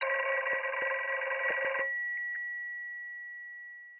Рингтон Звонок старого (домашнего) телефона